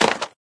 stone2.ogg